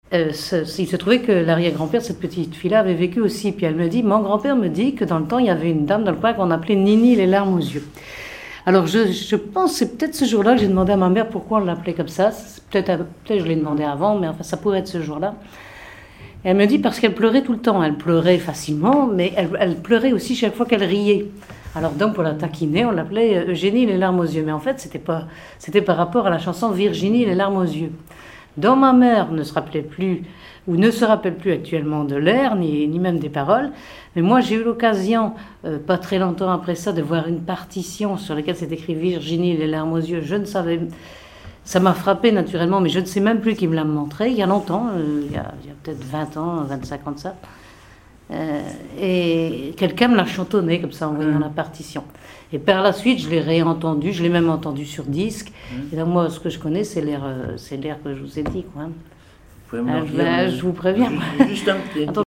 Conversation sur les musiciens de Saint-Pierre et Miquelon
Catégorie Témoignage